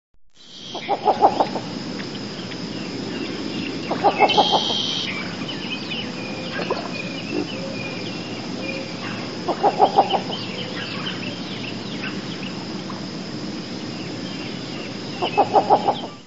Least Bittern
Bird Sound
Does not sing. Calls buzzy; also sharp chips. Wings of adult male make a high, buzzy trill.
LeastBittern.mp3